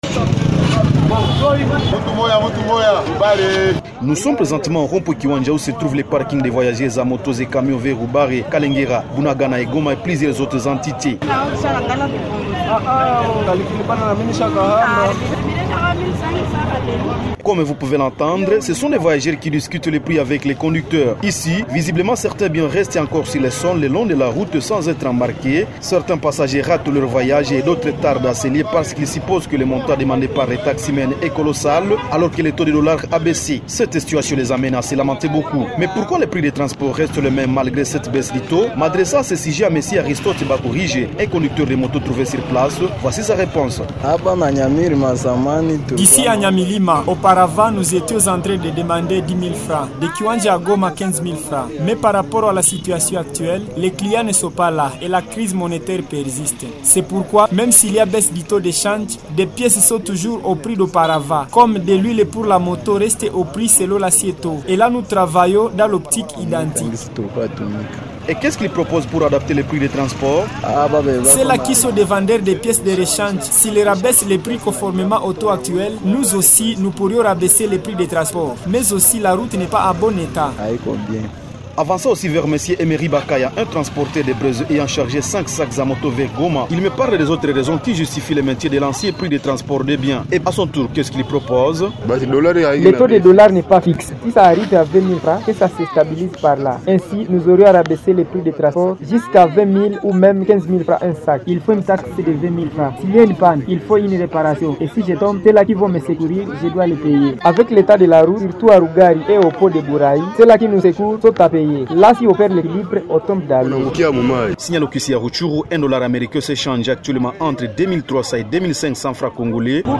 Ce constat a été fait ce 04 novembre 2025 par un reporter du bulletin « Habari za Mahali », lors d’une descente au rond-point Kiwanja.
FR-REPORT-COUP-DE-TRANSPORT-A-RUTSHURU.mp3